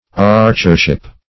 \Arch"er*ship\